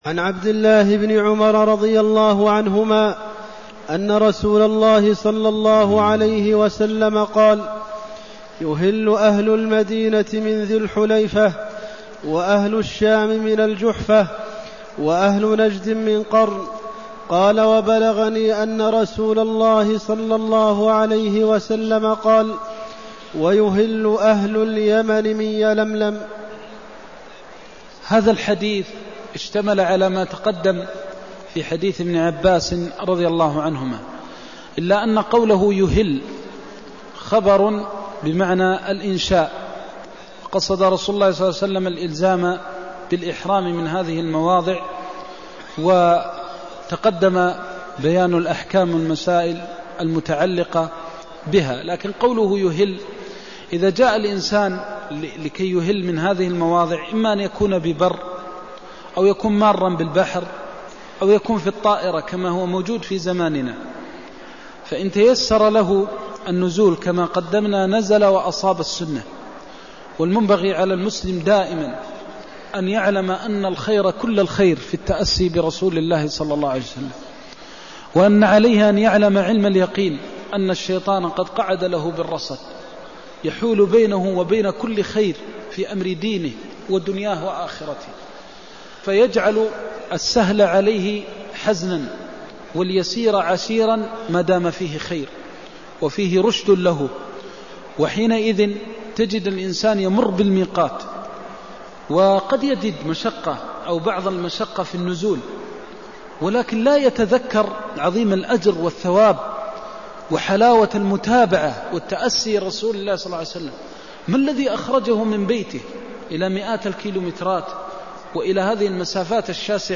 المكان: المسجد النبوي الشيخ: فضيلة الشيخ د. محمد بن محمد المختار فضيلة الشيخ د. محمد بن محمد المختار يهل أهل المدينة من ذي الحليفة (203)